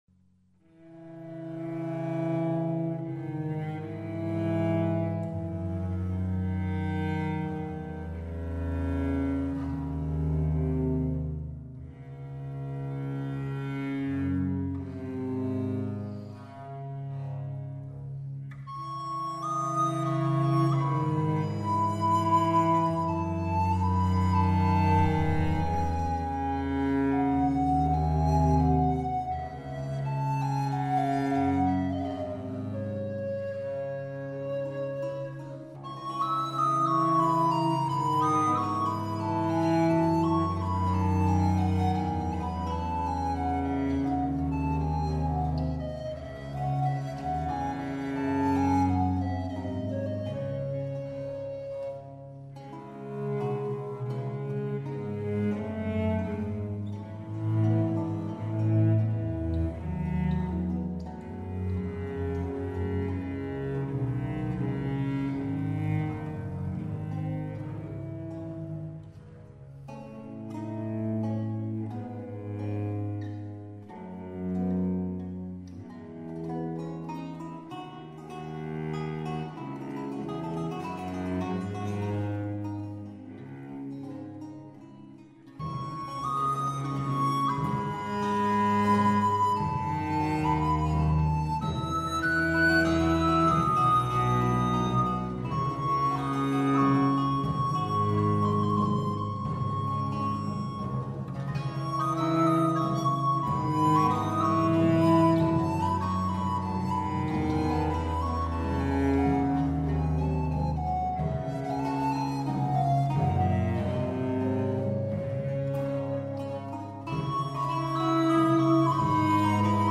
soprano
violoncello
liuto
viola da gamba
percussioni
organo, clavicembalo e direzione
Cantada a voce sola sopra il Passacaglie